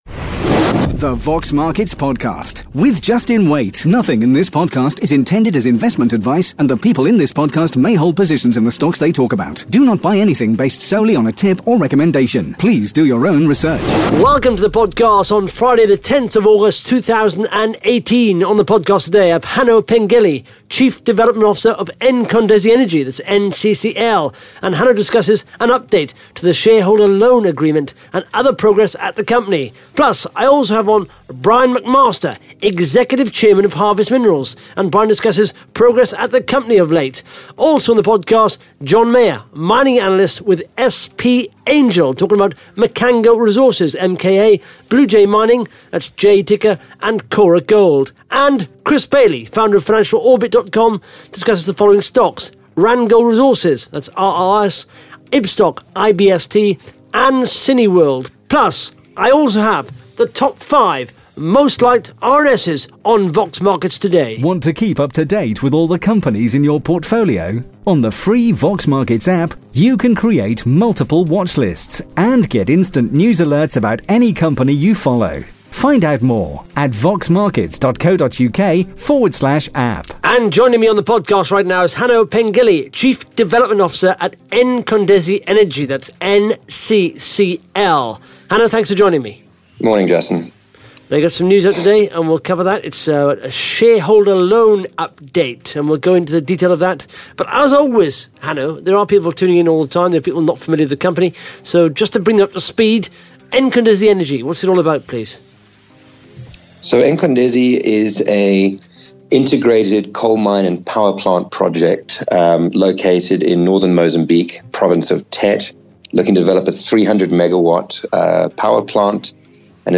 (Interview starts at 1 minute 20 seconds)